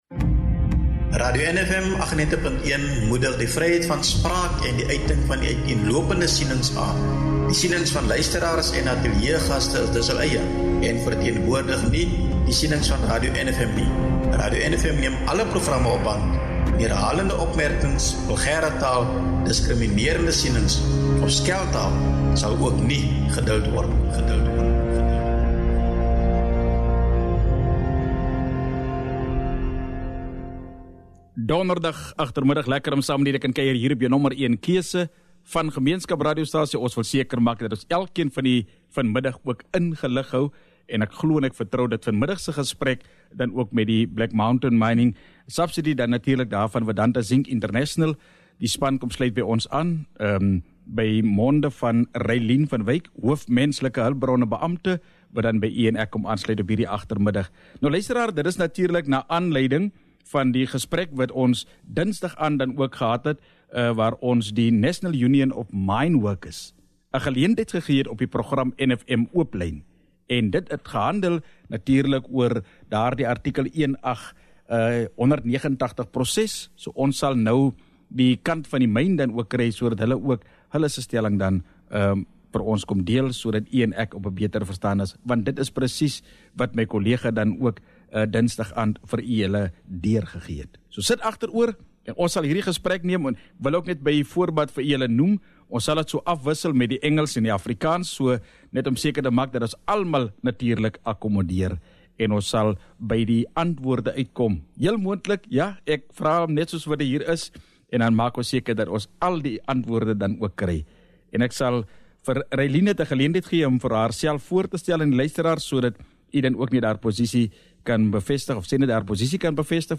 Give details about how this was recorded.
The studio engagement aims to provide clarity, address union claims, and outline the company’s position amid growing public and labour scrutiny. Attachments VEDANTA INTERVIEW 15 JAN 2026 (27 MB)